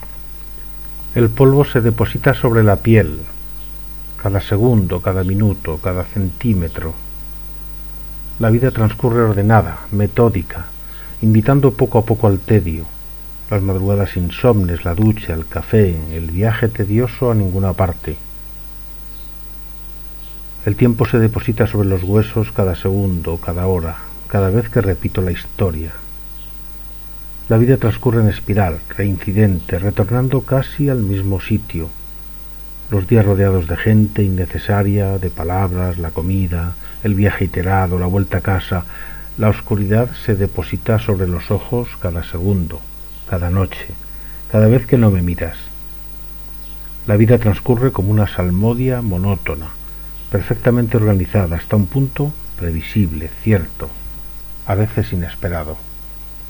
Lectura del poema Crimen organizado